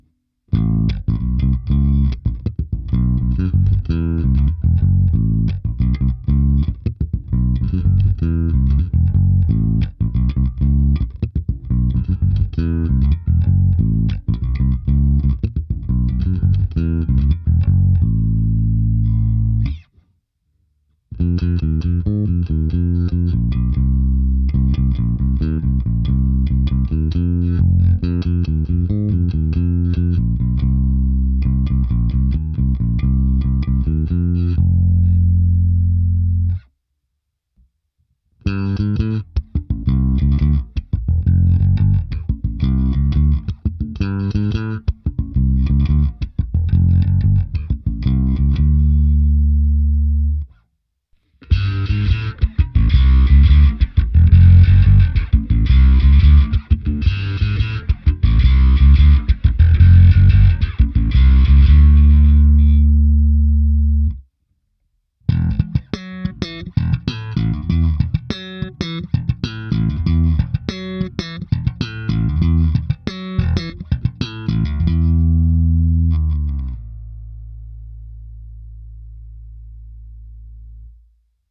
Zvuk je naprosto klasický Jazz Bass, zvláště v pasívním režimu.
Nahrávka se simulací aparátu na oba snímače, kde bylo použito i zkreslení a hra slapem